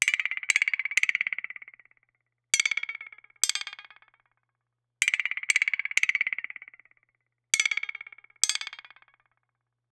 African Finger Bell Percussion Loop (96BPM).wav